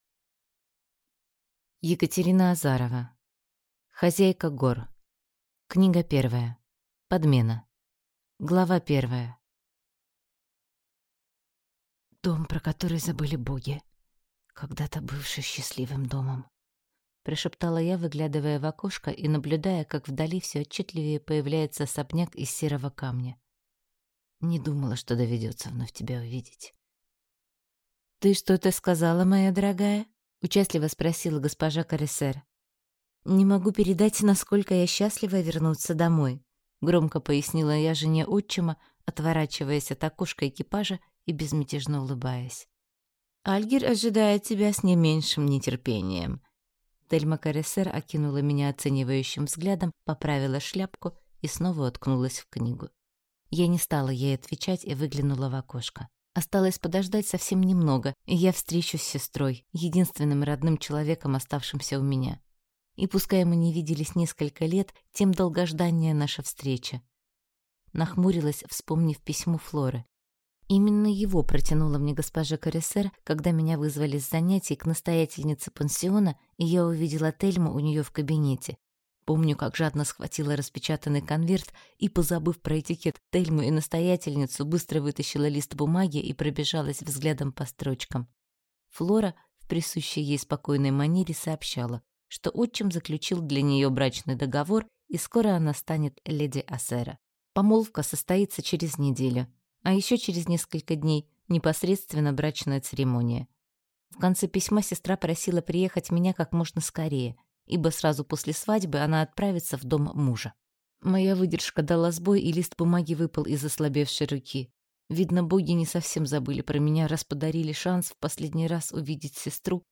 Аудиокнига Хозяйка гор. Подмена - купить, скачать и слушать онлайн | КнигоПоиск